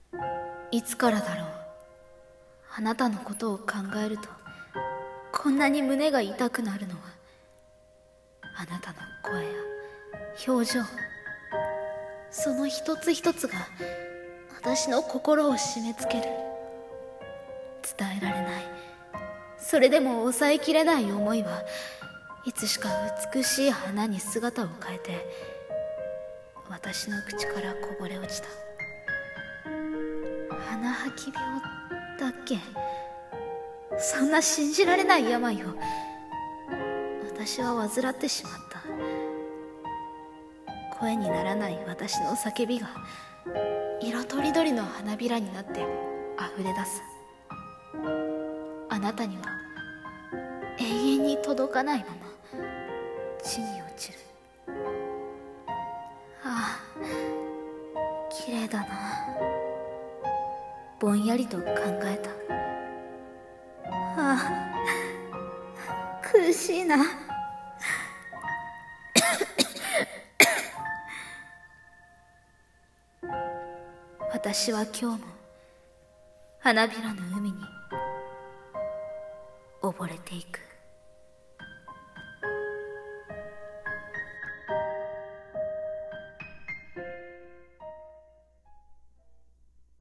【1人声劇】花吐き病